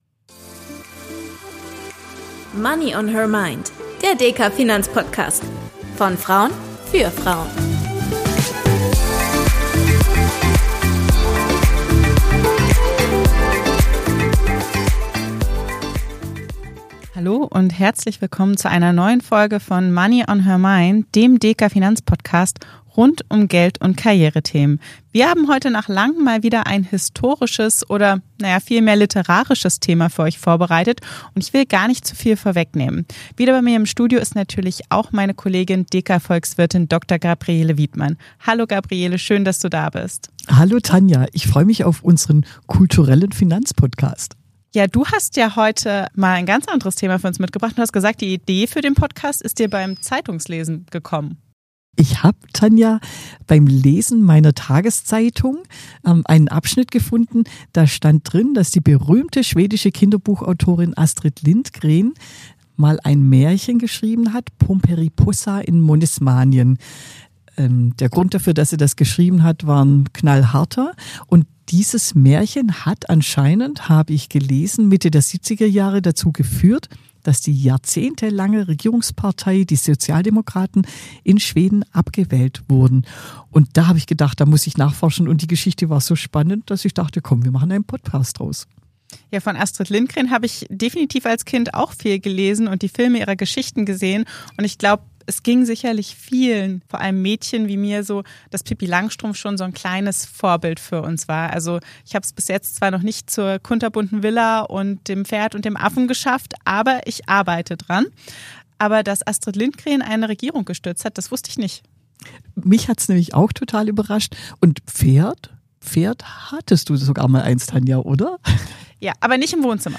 Das Märchen löste 1976 eine weitreichende Debatte über das schwedische Steuersystem und Grenzsteuersätze von über 100 % aus. Die Episode ordnet diesen historischen Impuls ein und zieht Parallelen zu aktuellen Herausforderungen des deutschen Steuersystems. Dabei diskutieren die Expertinnen, welche Lehren sich aus diesem Beispiel für den persönlichen Umgang mit Geld, die Eigenvorsorge und das Ziel der finanziellen Unabhängigkeit ziehen lassen.